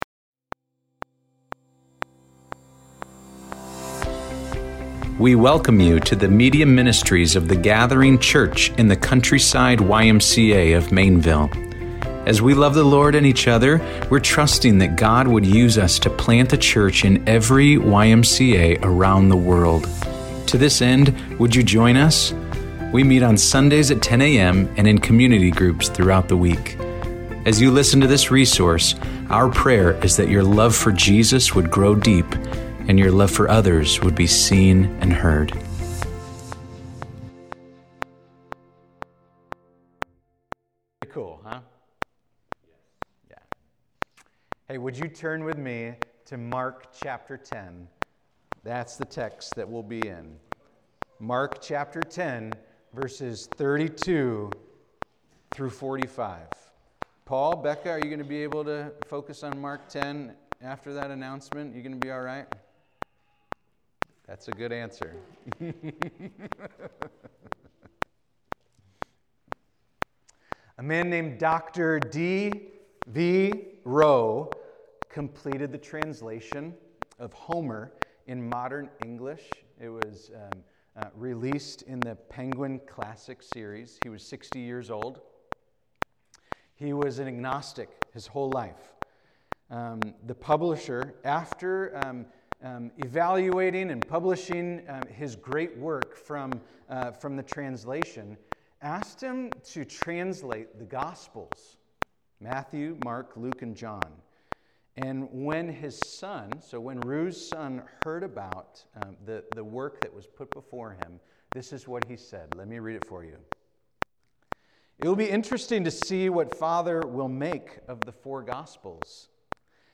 The Gathering City Maineville Sermons